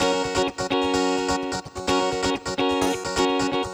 VEH3 Electric Guitar Kit 1 128BPM